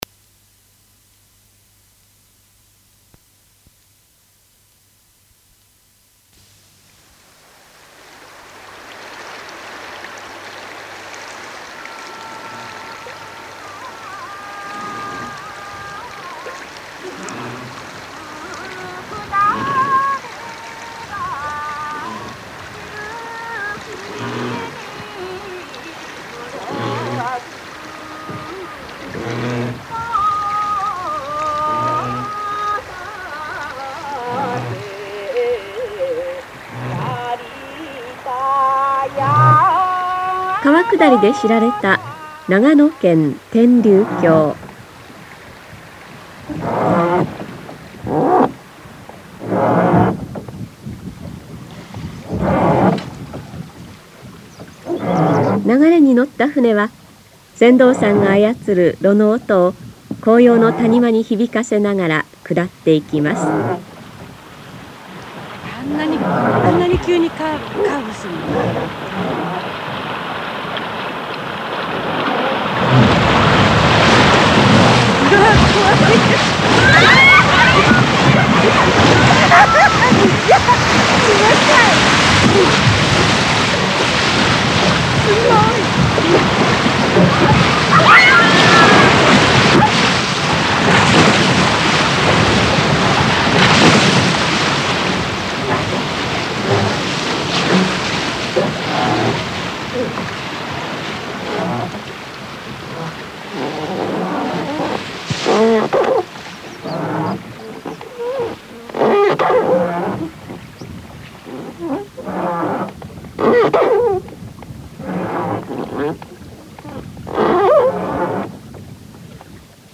投稿日: 2025年12月7日 2025年12月18日 音の風景⑤ 投稿日: 2025年12月6日 2025年12月18日 音の風景④ 投稿日: 2025年12月6日 2025年12月18日 音の風景③ 投稿日: 2025年12月6日 2025年12月18日 音の風景② 投稿日: 2025年12月6日 2025年12月18日 音の風景① この「音の風景の音声データ」は、番組が1985年4月1日に放送を開始した当初から、カセットテープに録音保管していたものをmp3に変換したものです。
NHKの「音の風景」とは、 日本各地の自然や人々の暮らしの中から聞こえる音を5分間のラジオ番組として届ける紀行番組で、1985年から続く長寿番組です。
番組の主な特徴 街の喧騒、祭りの音、自然の音など、その土地にしかない「音」を丁寧に集め、まるでその場にいるかのような臨場感を味わえます。